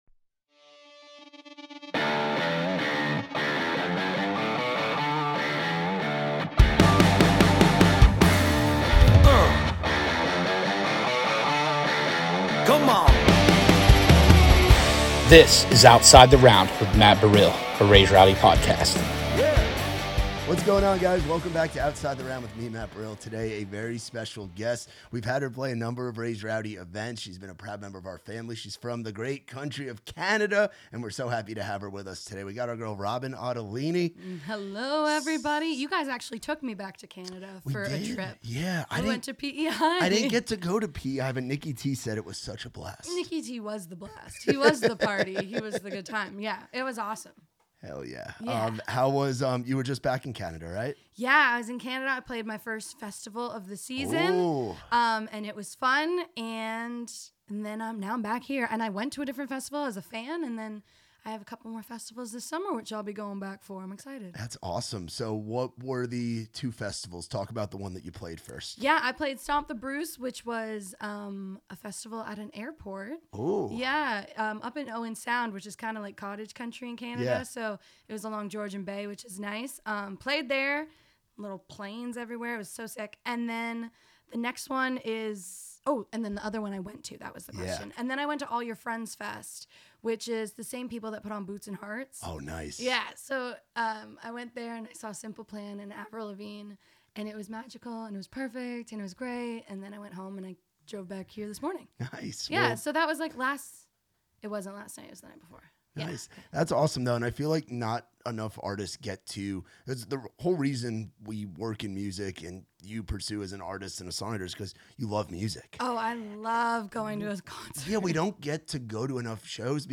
They perform live, share stories behind the songs, and we spin a few standout album tracks too.